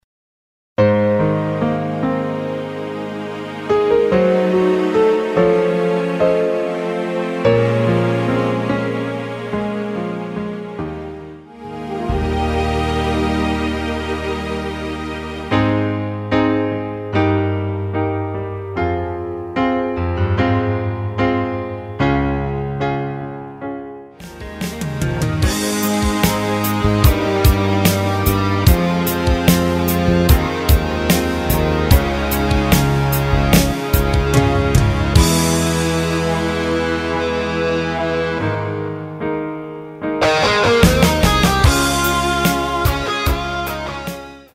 (-1) 내린 MR
원곡의 보컬 목소리를 MR에 약하게 넣어서 제작한 MR이며